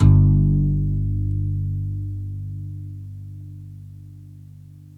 GUITARON 00R.wav